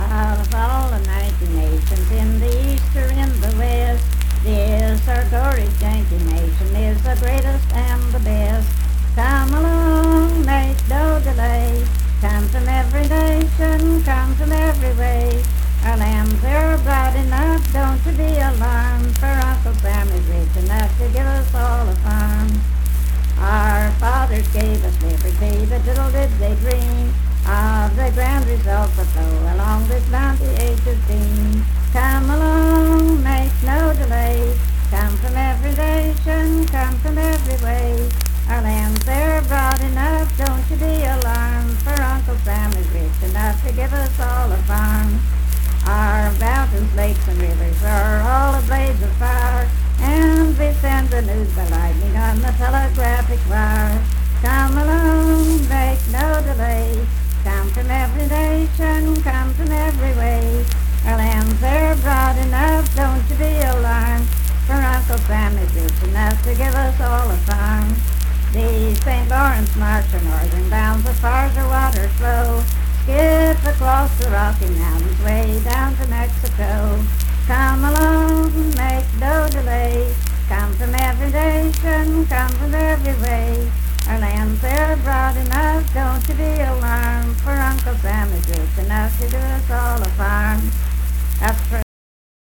Unaccompanied vocal music performance
Political, National, and Historical Songs
Voice (sung)